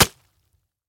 Knife_Flesh.wav